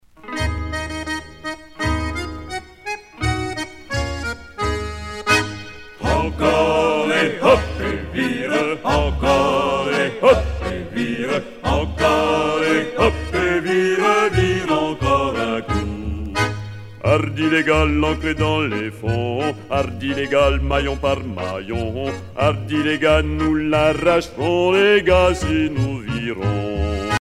Chanson à virer
Pièce musicale éditée